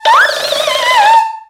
Cri de Brocélôme dans Pokémon X et Y.